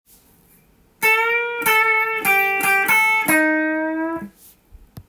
Cマイナーペンタトニックスケールを混ぜて弾いています。
①のフレーズはCメジャーペンタトニックスケールから始まり
最後はマイナーペンタトニックスケールで着地しています。
明るいけど最後は暗くなる　玄人系フレーズです。